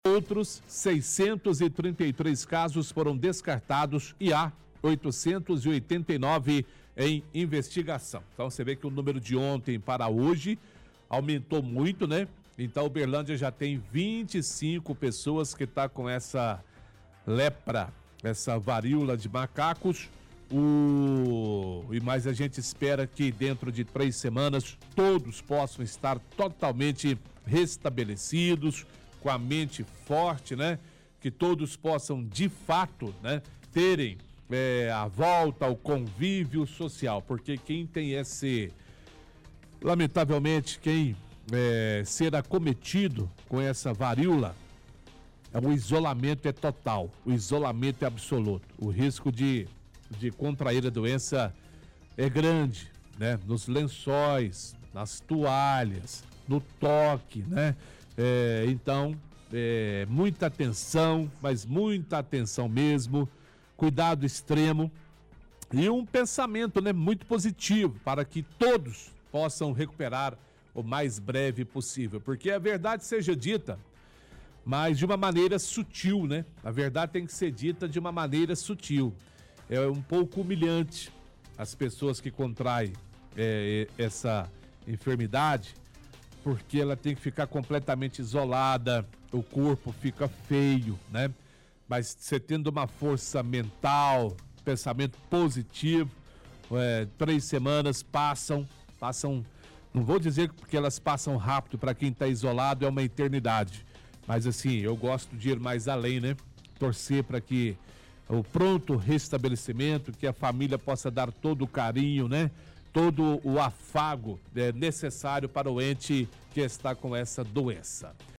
-Apresentador lê dados da Secretaria de Estado de Saúde.